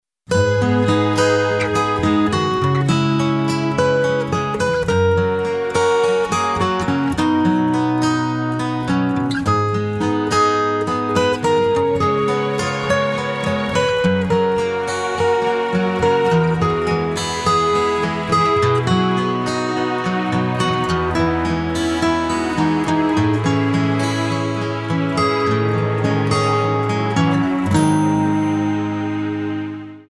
Tonart:Dbm Multifile (kein Sofortdownload.
Die besten Playbacks Instrumentals und Karaoke Versionen .